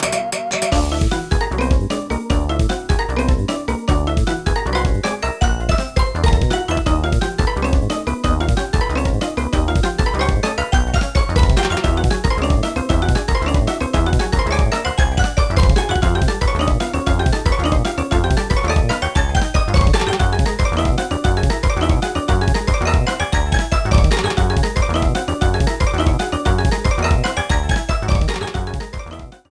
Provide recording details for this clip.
Ripped from the game applied fade out to last two seconds